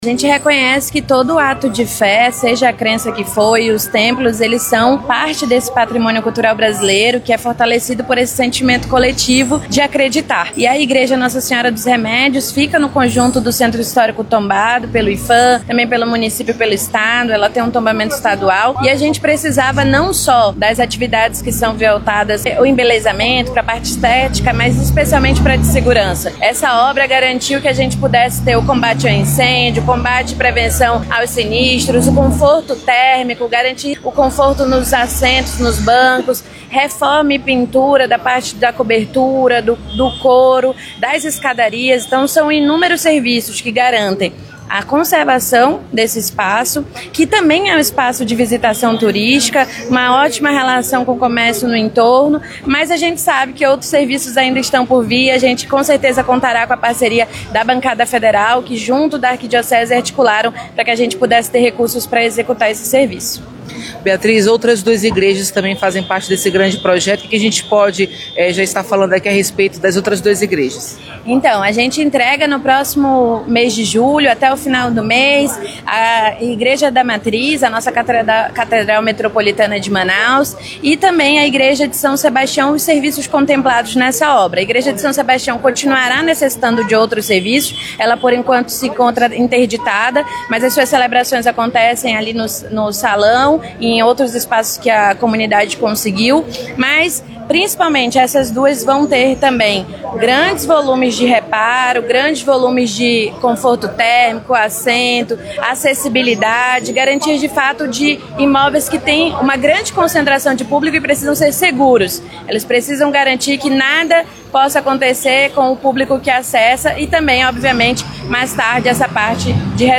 Para tornar o projeto possível, houve a colaboração técnica do Instituto Municipal de Planejamento Urbano (Implurb) e da Secretaria de Estado da Cultura (SEC), que contribuíram na elaboração da proposta de engenharia e orçamento, explica a superintendente do Iphan, Beatriz Calheiros.